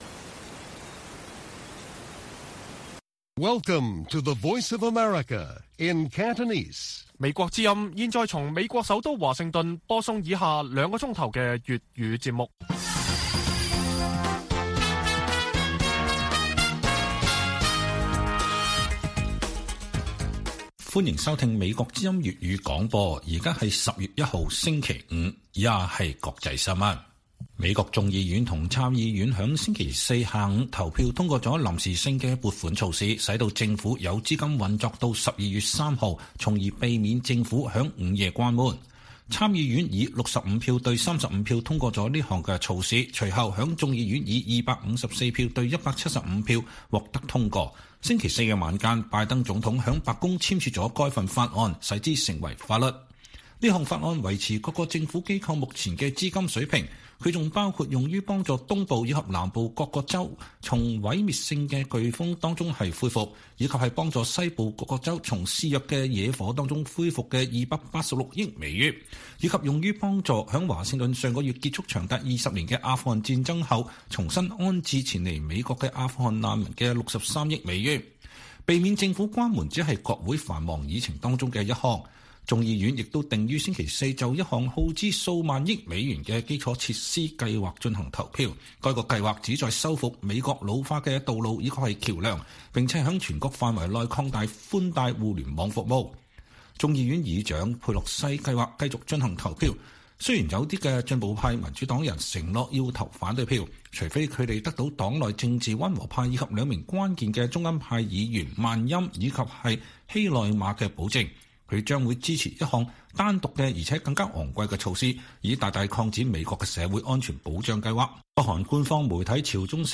粵語新聞 晚上9-10點：美國國會批准臨時撥款使聯邦政府繼續運轉